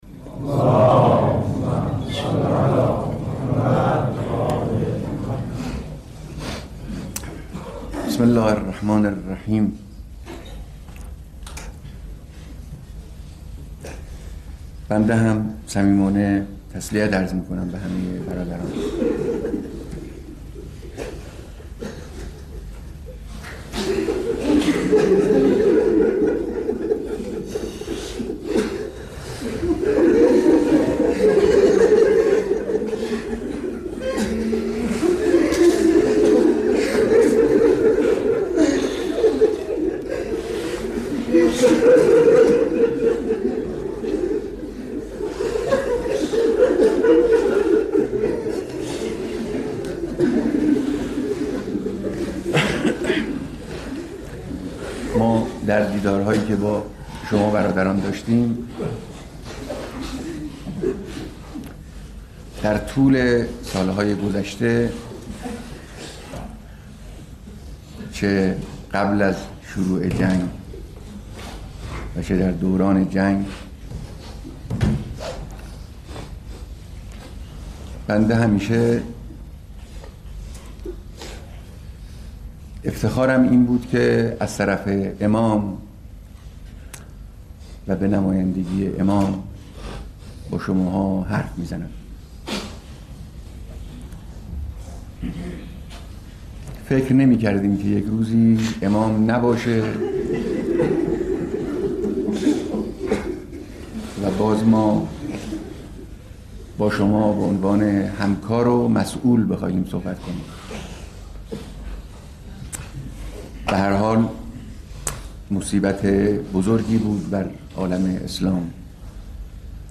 بیانات در مراسم بیعت فرماندهان سپاه